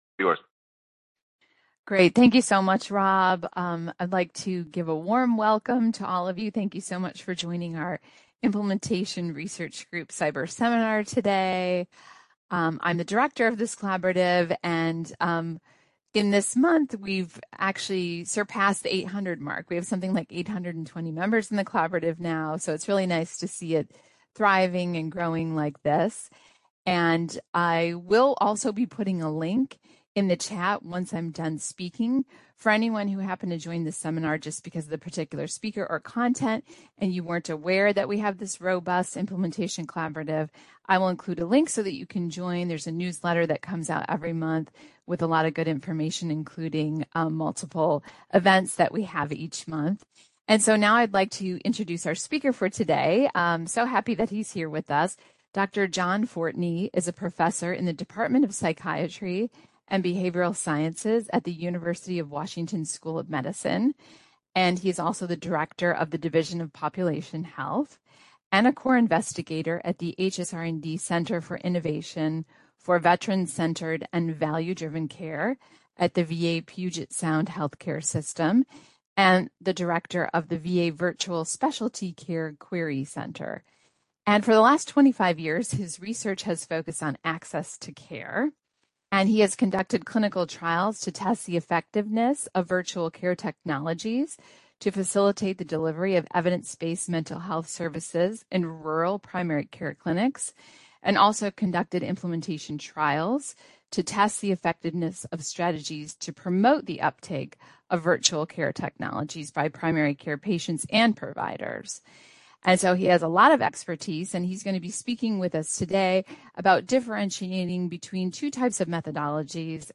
This seminar will clarify the similarities and differences of these trial types for funders, researchers and policy makers. In addition, recommendations will be offered to help investigators choose, label, and operationalize the most appropriate trial type to answer their research question.